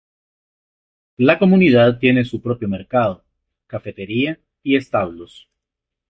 mer‧ca‧do
Pronunciado como (IPA)
/meɾˈkado/